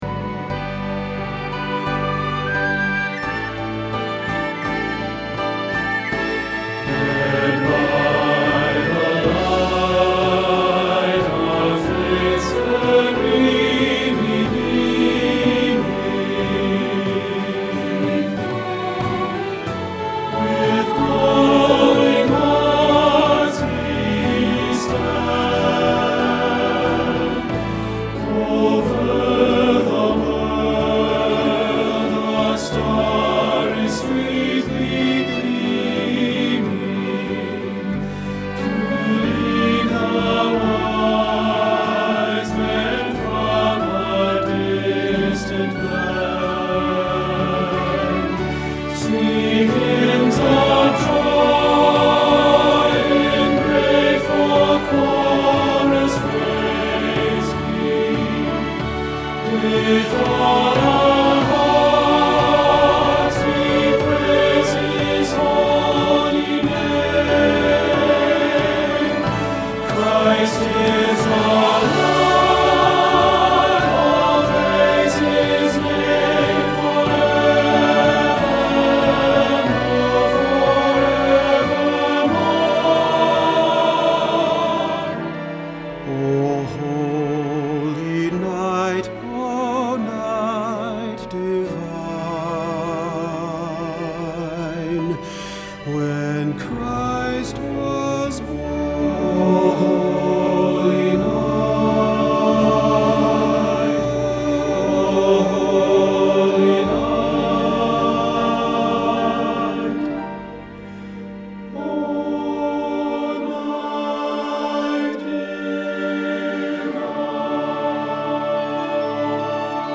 O Holy Night – Tenor – Hilltop Choir
O Holy Night – Tenor Hilltop Choir